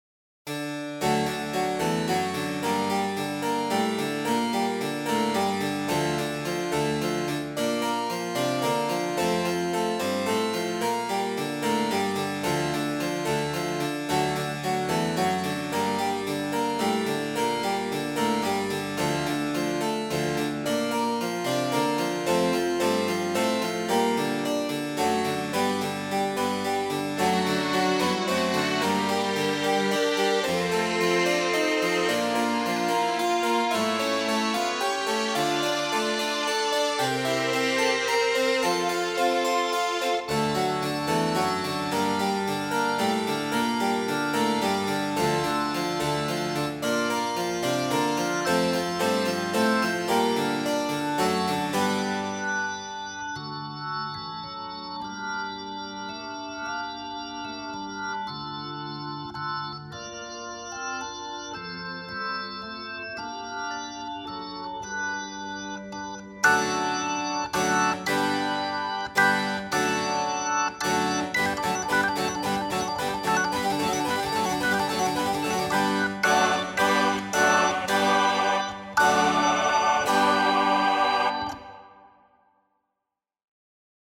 Stycke nummer två ´Rent a sense´ har lite intressanta stråkar i sig. Mellan Mellotron mkII och 400 kom en modell  som hette M300 och stråkarna i den låter guld, speciellt i luftiga partier.
Sen kommer orgeln och ett kul ljud från vänsterkanten, en vibrafon från M-tron.
Trummor, Hammond, Mjukvaror, flöjt och komposition/arr/produktion
Ibanez bas och Starfield gitarr